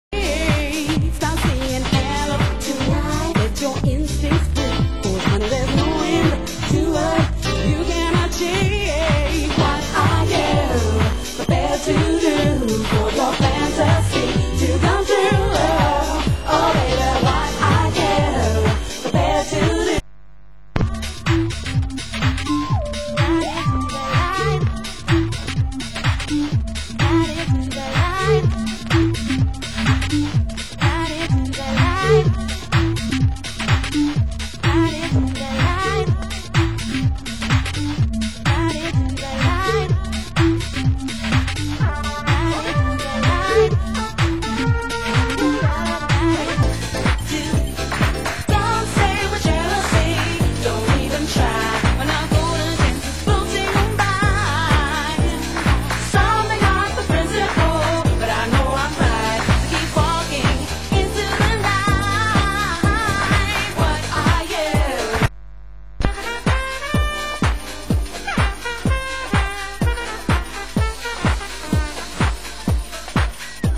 Genre: UK House
Keyboards